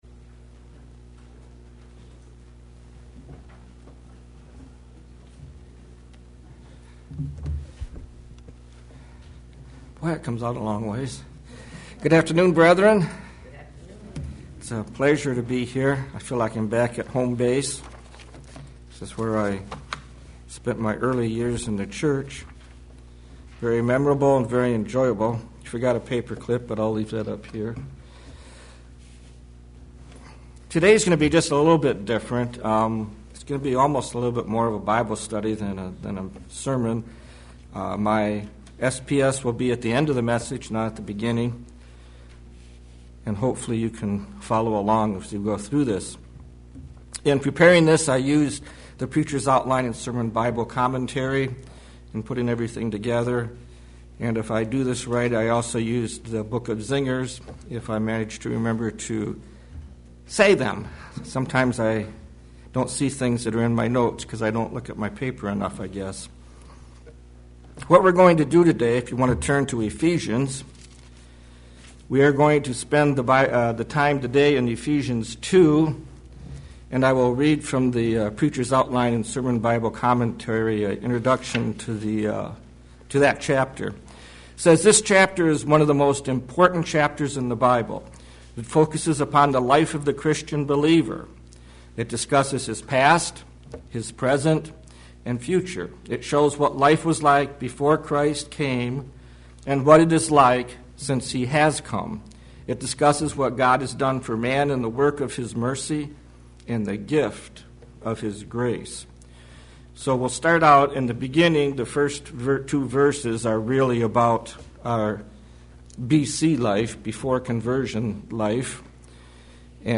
Given in Ann Arbor, MI Flint, MI